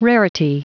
Prononciation du mot rarity en anglais (fichier audio)